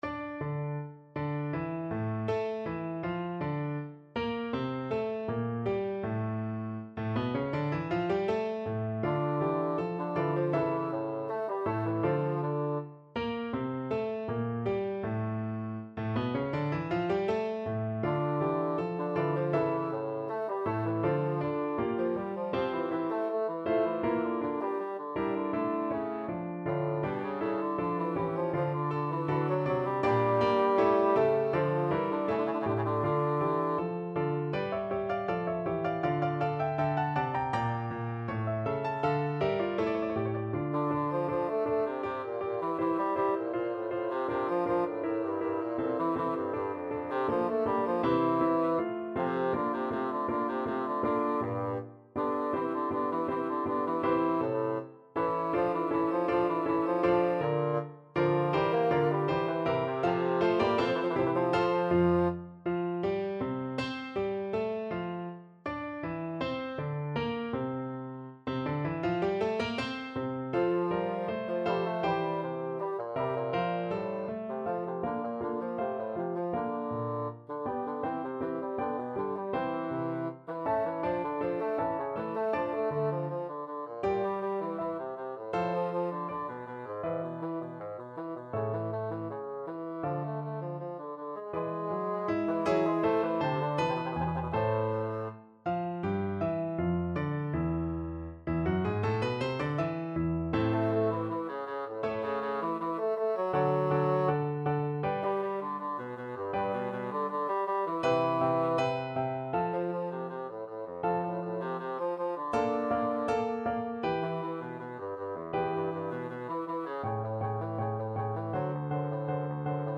Bassoon
Andante e spiccato
F major (Sounding Pitch) (View more F major Music for Bassoon )
4/4 (View more 4/4 Music)
Classical (View more Classical Bassoon Music)